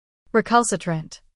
播放发音）意为“顽固反抗的”，常用来形容人或事物表现出强烈的抵制和不服从态度。
recalcitrant-pronunciation.mp3